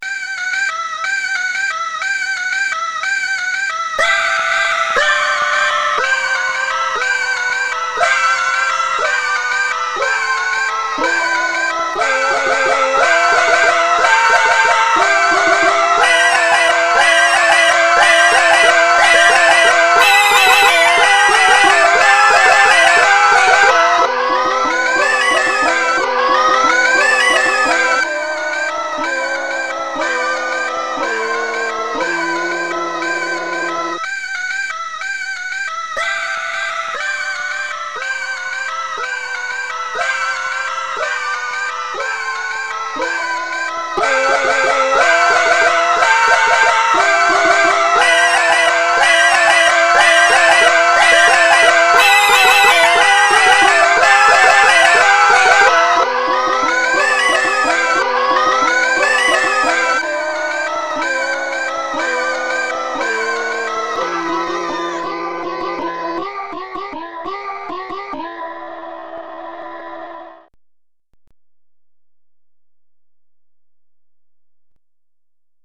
Young Link (Soprano), Wind Waker Link (Alto), Adult Link (Tenor), and Twilight Princess Link (Bass) wish you a Merry Christmas and a Happy New Year with their, er, rousing rendition of a Christmas classic.